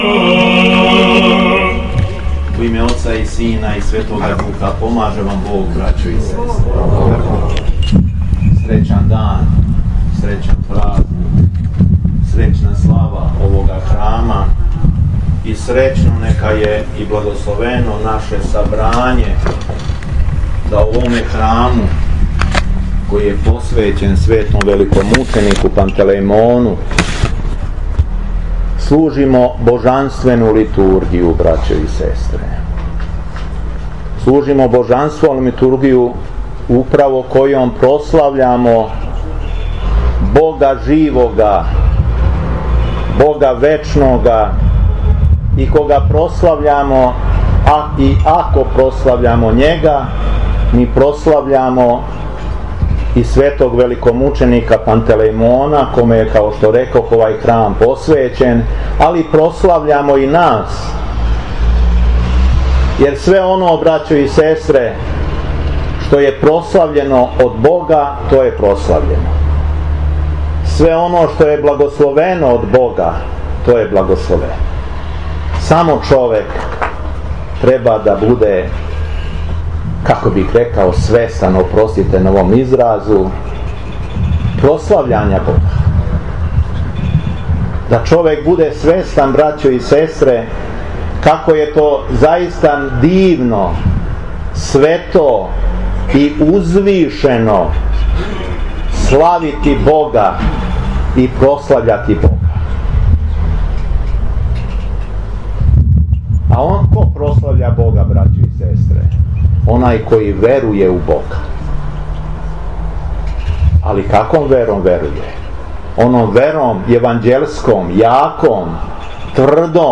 Беседа Епископа шумадијског Г. Јована
У четвртак 9. августа 2018. године, на дан Светог Пантелејмона и Светог Климента, Његово Преосвештенство Епископ шумадијски Господин Јован служио је Свету Архијерејску Литургију у храму Светог Пантелејмона у Станову.